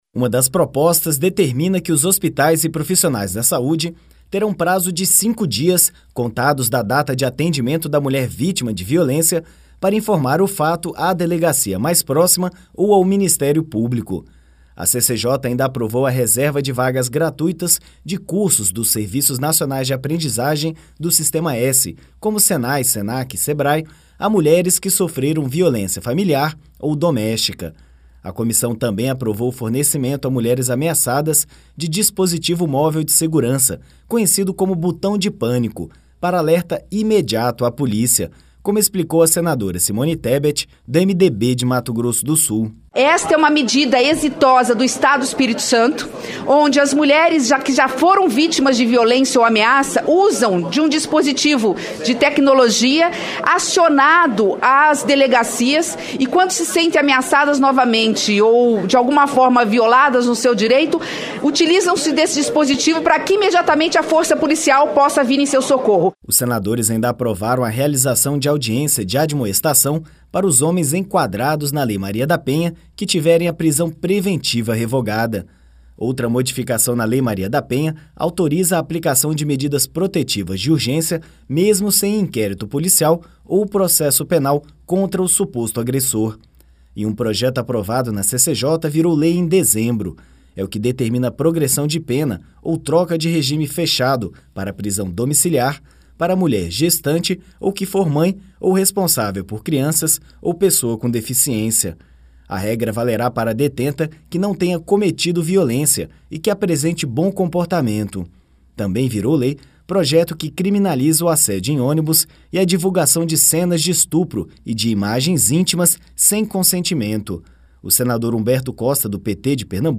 A reportagem é de